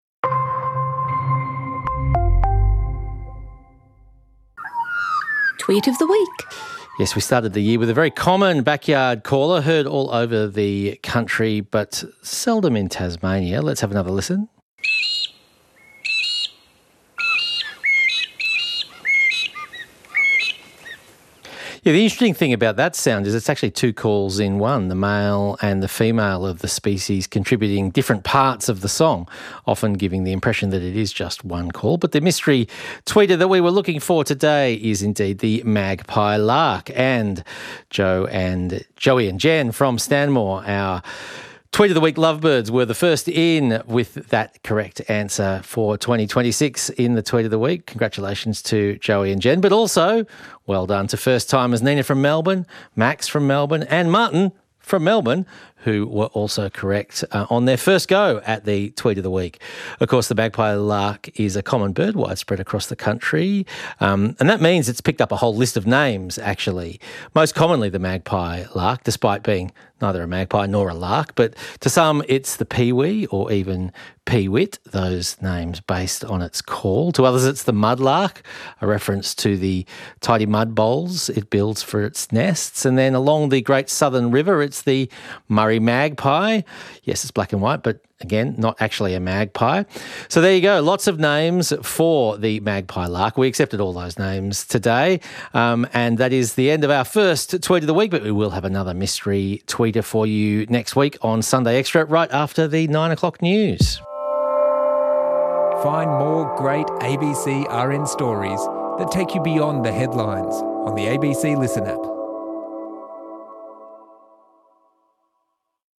This week's mystery caller is common, widespread and definitely not a Magpie! – it's the Magpie-Lark.